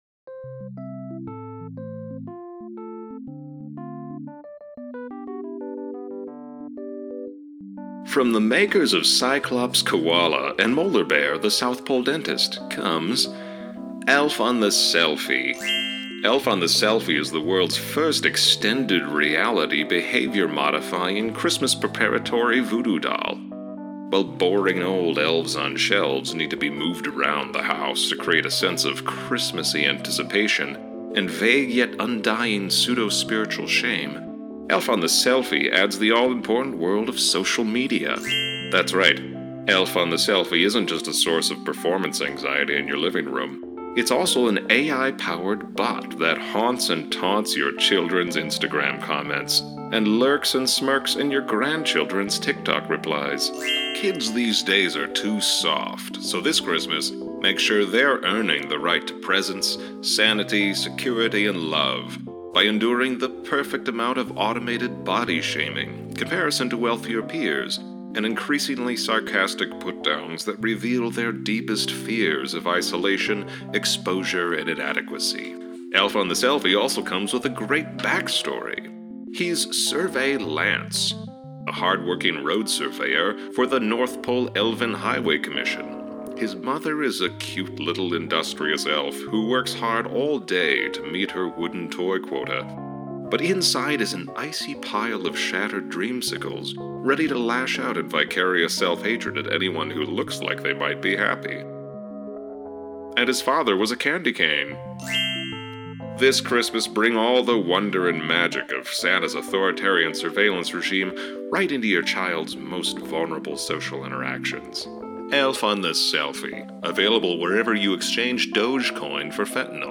Ad for the new product Elf on the Selfie, a social media-era update to the Elf on the Shelf.